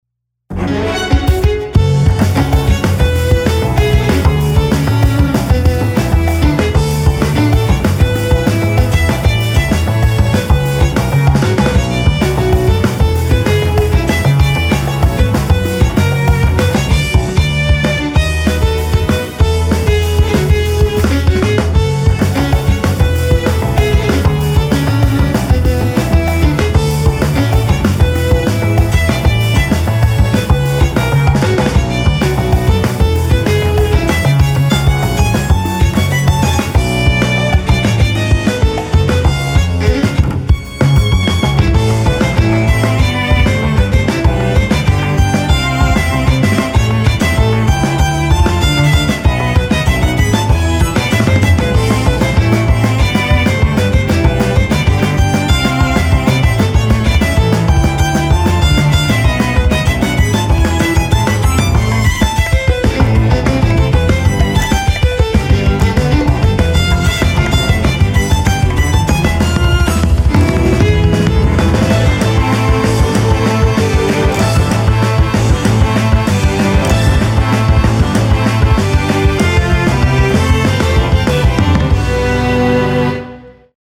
ロールプレイングゲームの戦闘用BGMの制作中のデモです。
RPGランダムエンカウトの戦闘シーン用、bpm:192